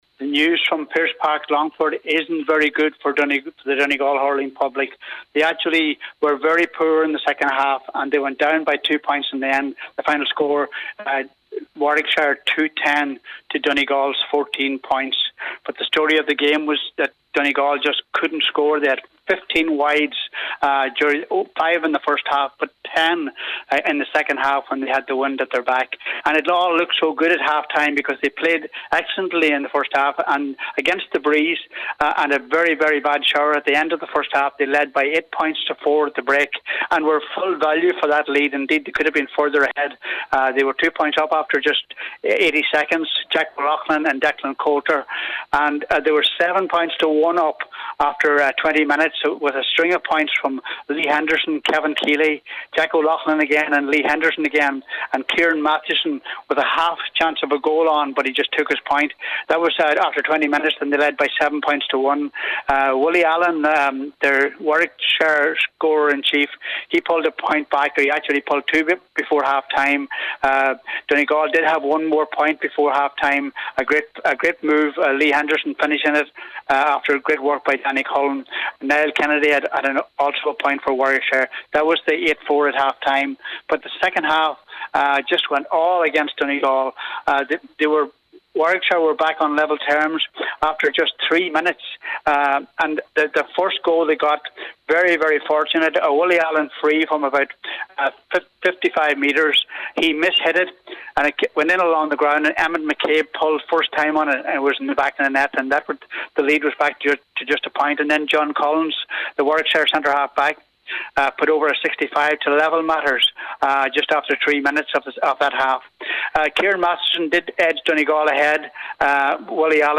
reports for Highland Radio Sport…